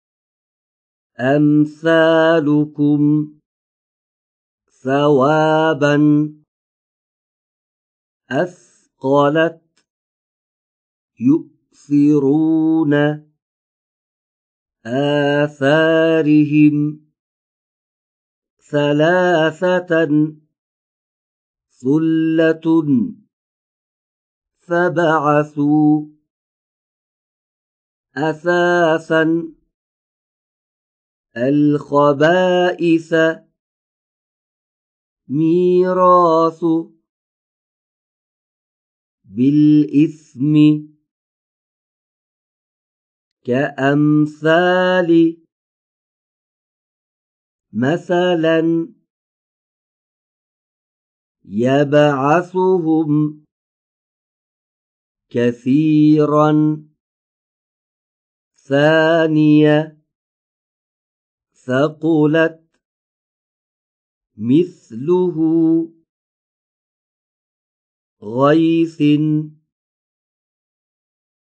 ۲- هوا را از محل برخورد سر زبان و پشت سر دندان‌های پیشین بالا به صورت سایشی خارج کنید.
۴- فاصله بین سقف و سطح دهان را کم کنید تا حرف «ث» نازک تلفظ شود.